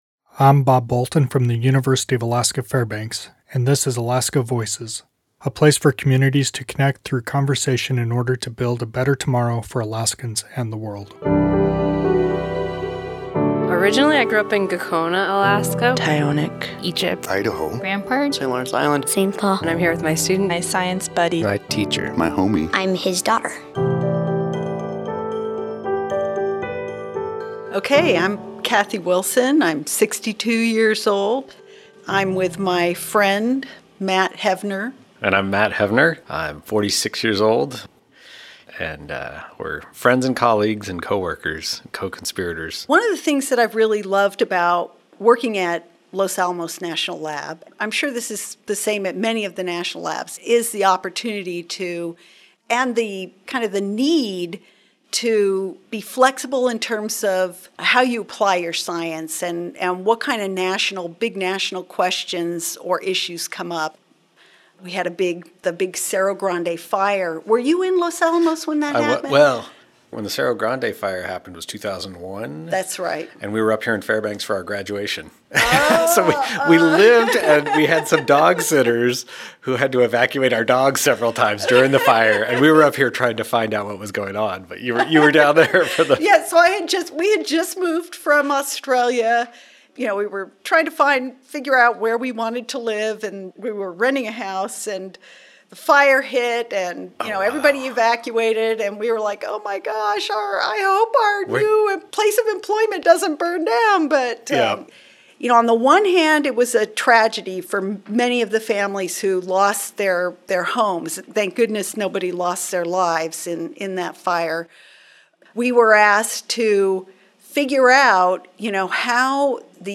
Music: “Arctic Evening” by Marcel du Preez